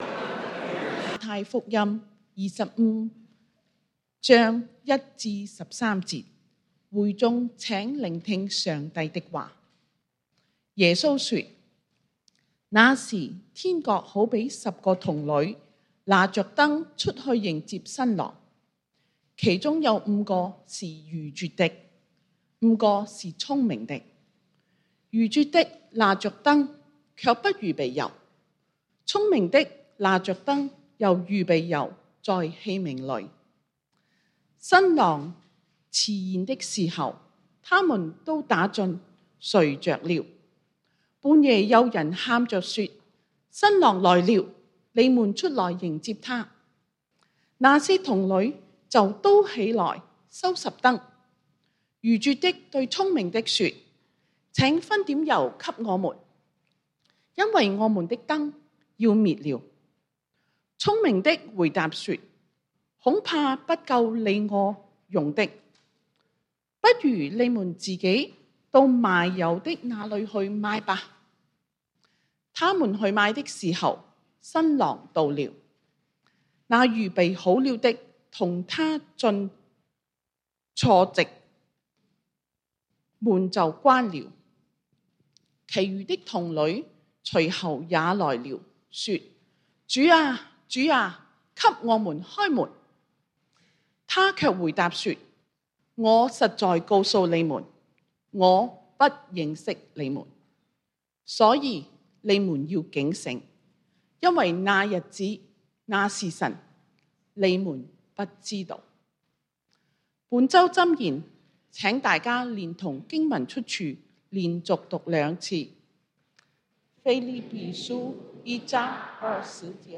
11/12/2023 講道經文：馬太福音 Matthew 25:1-13 本週箴言：腓立比書 Philippians 1:20 「照着我所切慕、所盼望的，沒有一事叫我羞愧。